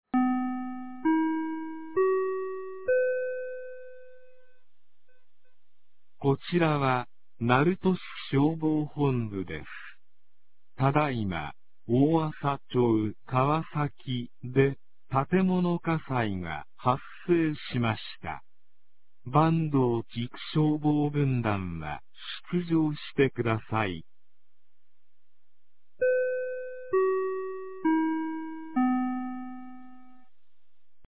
2025年11月17日 16時45分に、鳴門市より大麻町-川崎、大麻町-津慈、大麻町-板東、大麻町-桧へ放送がありました。